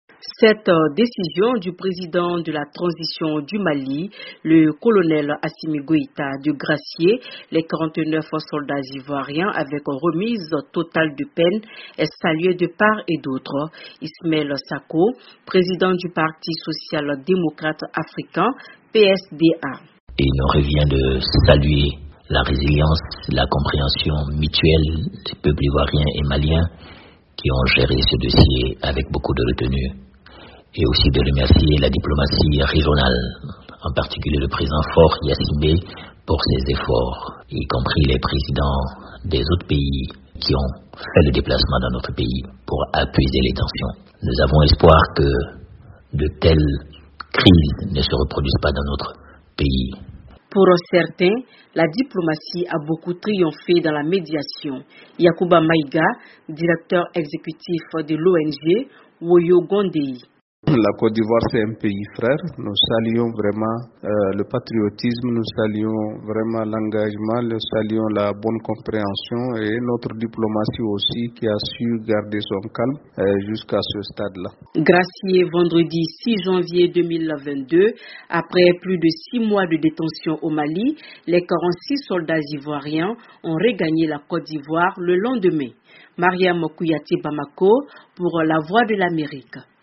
Après la grâce accordée vendredi par le président de la transition malienne, le colonel Assimi Goïta aux 46 sldats ivoiriens,. quelques maliens réagissent à Bamako.